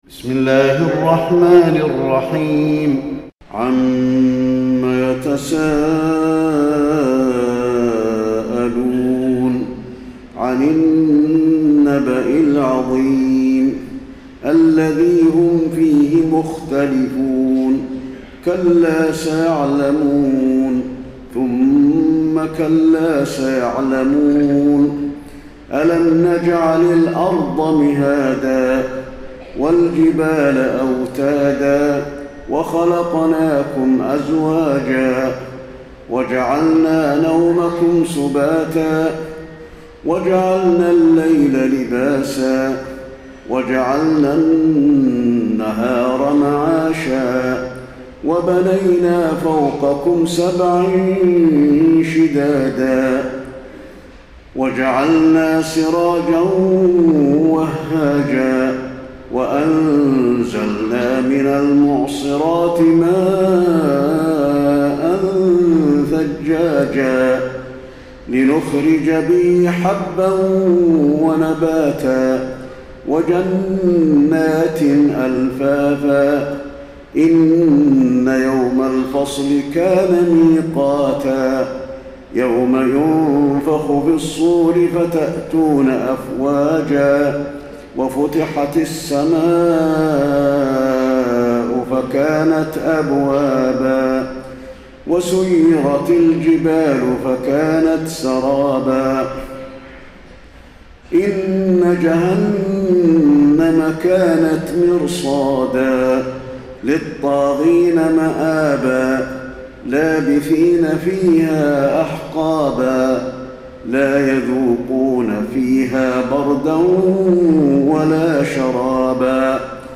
تراويح ليلة 29 رمضان 1432هـ من سورة النبأ الى البلد Taraweeh 29 st night Ramadan 1432H from Surah An-Naba to Al-Balad > تراويح الحرم النبوي عام 1432 🕌 > التراويح - تلاوات الحرمين